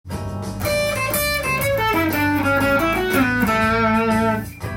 ②のフレーズは、AマイナーペンタトニックスケールAミクソリディアンスケール
最後にAメジャーペンタトニックスケールを弾いています。
①同様　コードにマッチした感じがします。